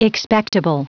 Prononciation du mot expectable en anglais (fichier audio)
Prononciation du mot : expectable